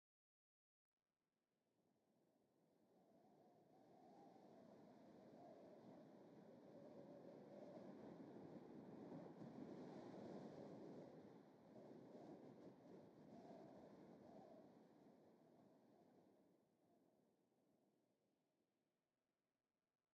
howlingwind1.ogg